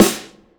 • Boom Bap Hip Hop Snare One Shot F Key 62.wav
Royality free snare one shot tuned to the F note. Loudest frequency: 2586Hz
boom-bap-hip-hop-snare-one-shot-f-key-62-PAK.wav